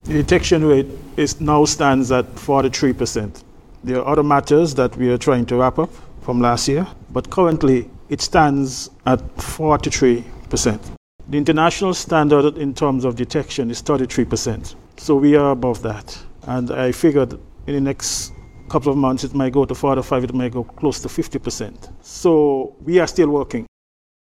Assistant Commissioner of Police, Andre Mitchell, speaking at the Police Force’s first press conference for 2026 on Jan. 7th.